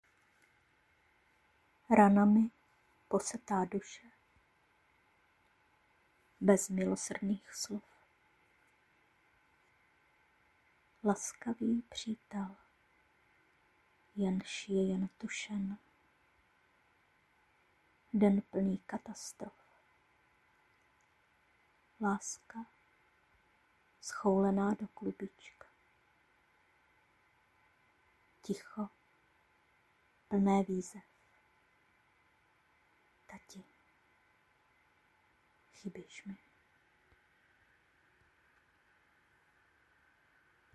Tvá básnička zní o to dojemněji, že máš hlásek jako bezbranné děvčátko... samozřejmě chápu, źe tohle bolí v každém věku, i po letech.